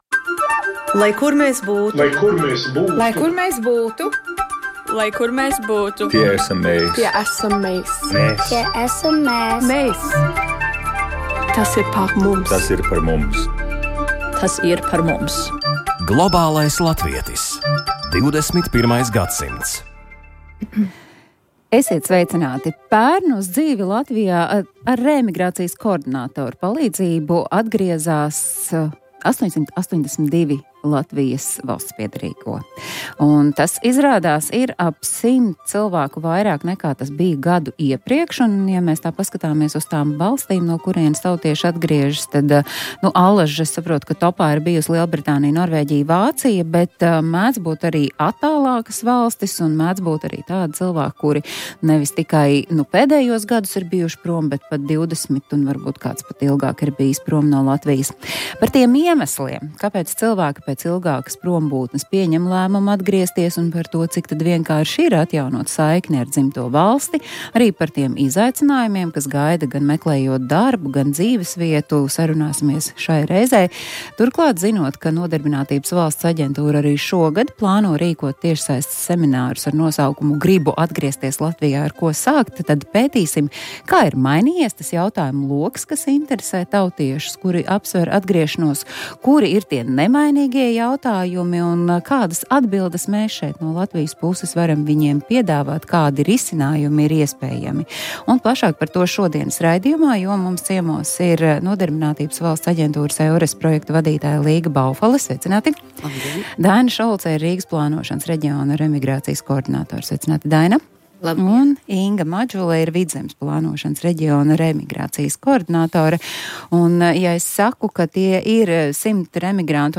Kāpēc cilvēki pēc ilgākas prombūtnes pieņem lēmumu atgriezties, un par to, cik tad vienkārši ir atjaunot saikni ar dzimto valsti, arī par izaicinājumiem, kas gaida, gan meklējot darbu, gan dzīvesvietu, saruna šajā reizē.